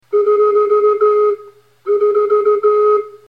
Sol_diese.mp3